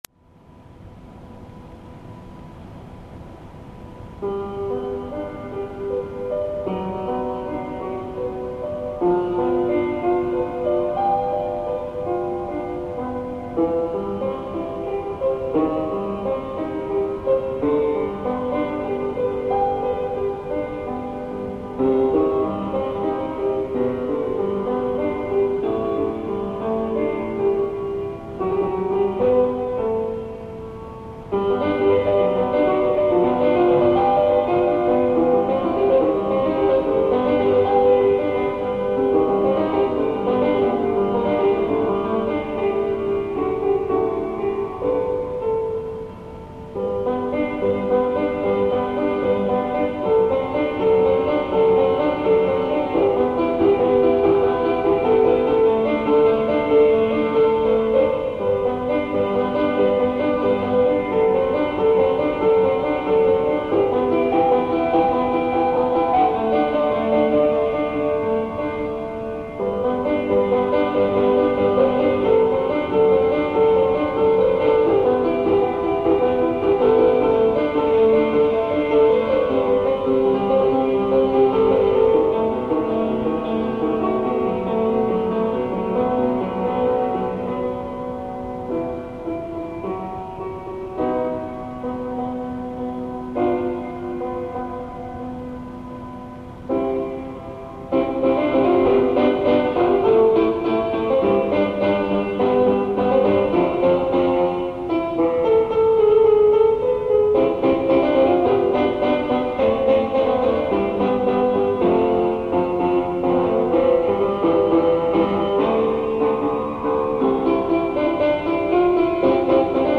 Live Piano Compositions
These exciting recordings are taken off of a low quality tape I recorded with a simple recorder at age 15 to archive some of my very first compositions.